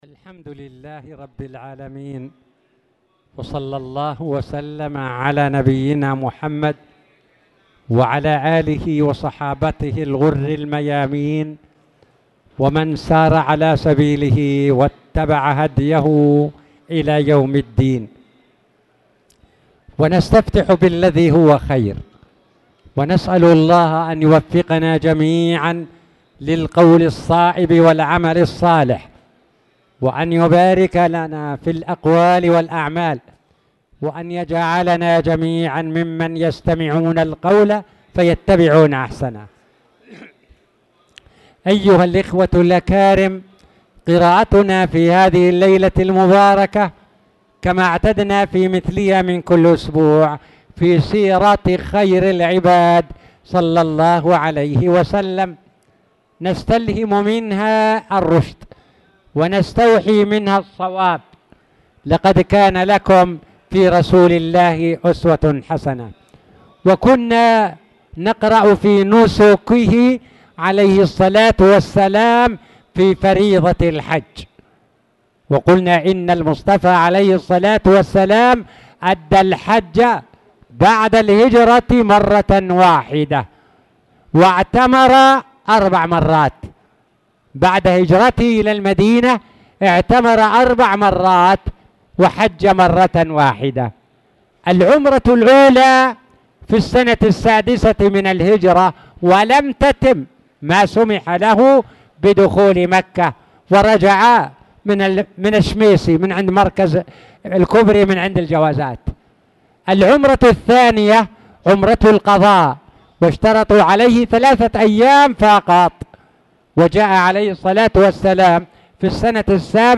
تاريخ النشر ٢٢ ذو القعدة ١٤٣٧ هـ المكان: المسجد الحرام الشيخ